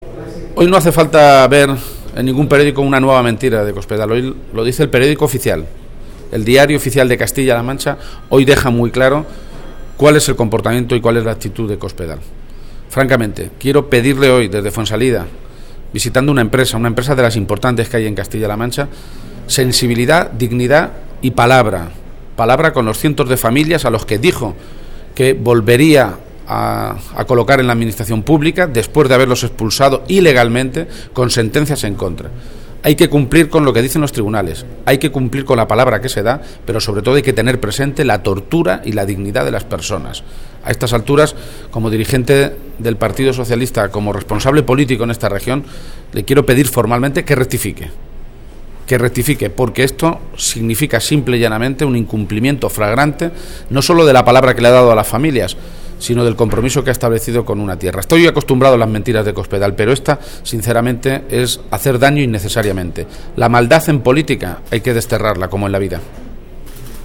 García-Page, que ha realizado estas declaraciones momentos antes de visitar la empresa de calzado “Pablosky” de Fuensalida, ha pedido a Cospedal, “sensibilidad y dignidad” para los cientos de familias con los que se comprometió a readmitir a esos interinos en la administración regional después de haberlos expulsado ilegamente.